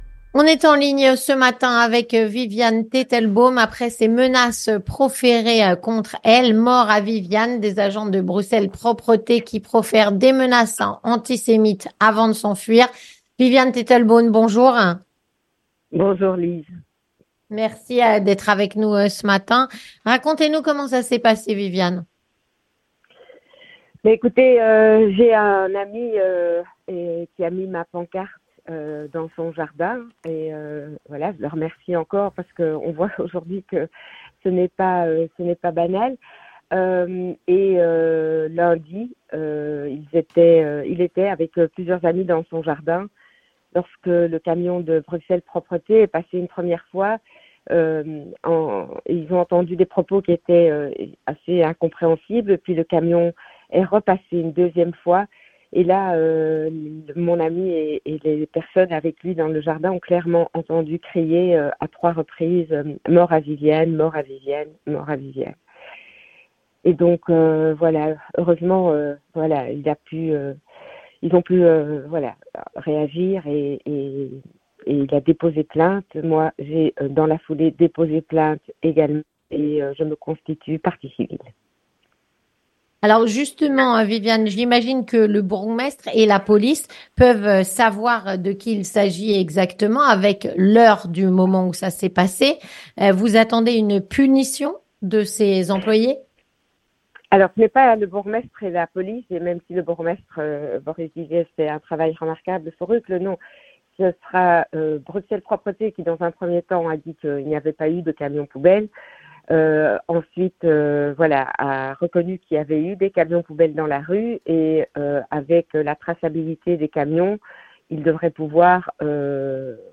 Voici la réaction de Viviane teitelbaum, en direct, dans notre journal de 9H.